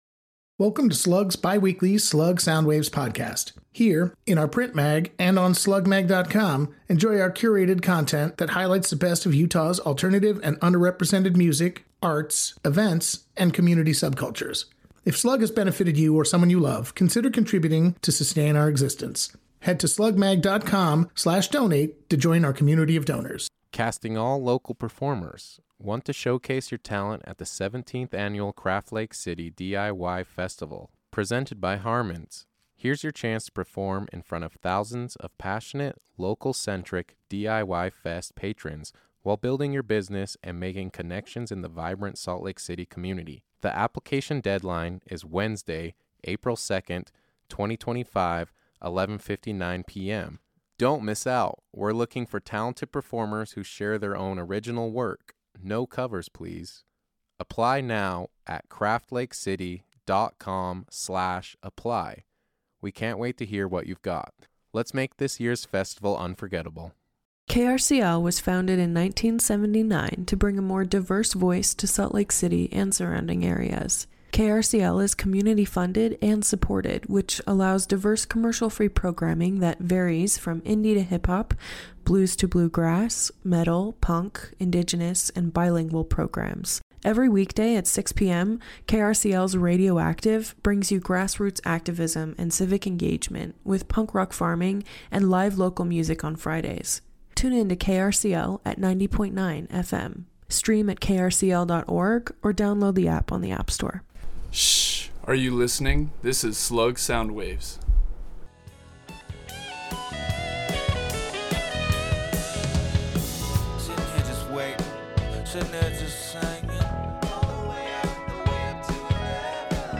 On this episode of SLUG Soundwaves, the band discusses their sound, origin and upcoming projects.